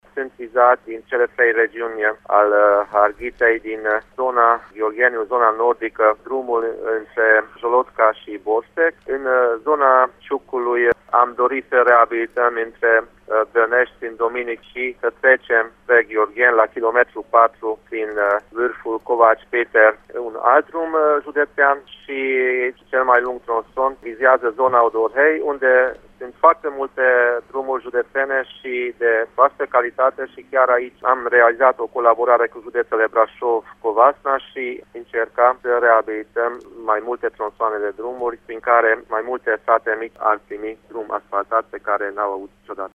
Noul proiect cuprinde zonele Jolotca – Borsec, Dăneşti – Sândominic şi cele din jurul Odorheiului Secuiesc, a explicat, pentru Radio Tîrgu-Mureş, preedintele Consiliului Judeţean Harghita, Borboly Csaba: